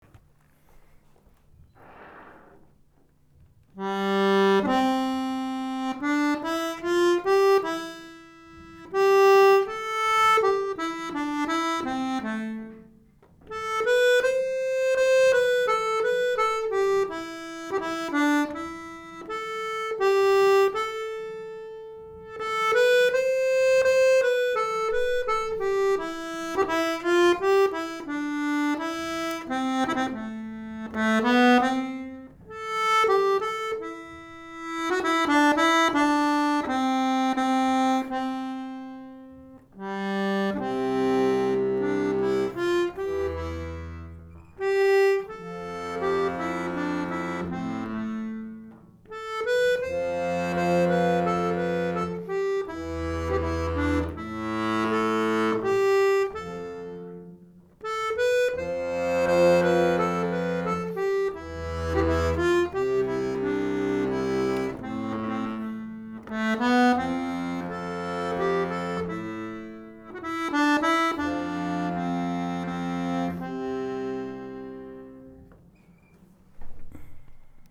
Accordéon diatonique
Deux thèmes Ecossais abordés lors d’un magnifique          Bridget O’Malley (pdf)
blues Ecossais sur le fameux thème de l’amour perdu        Polka Ecossaise